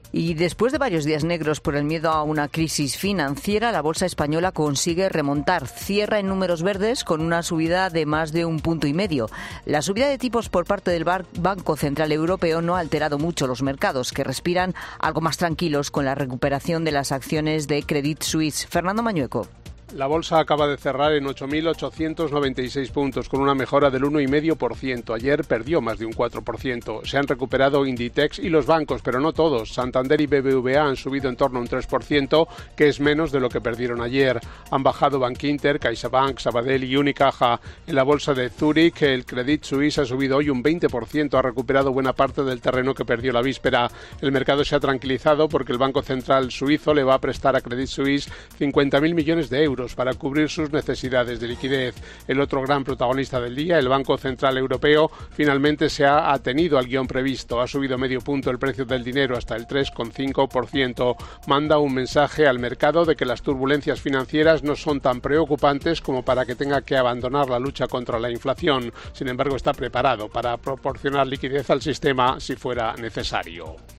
Te da más detalles el experto en Bolsa